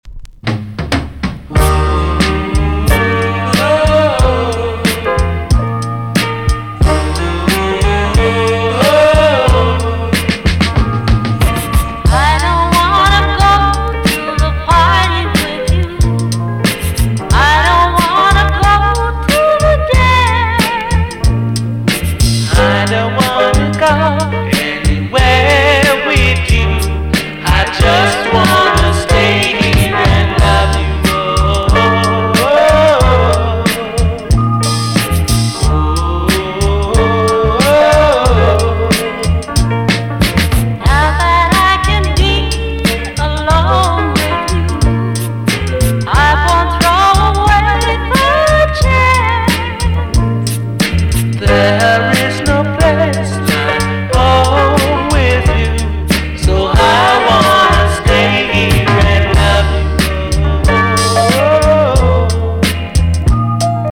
TOP >LP >VINTAGE , OLDIES , REGGAE
B.SIDE EX- 音はキレイです。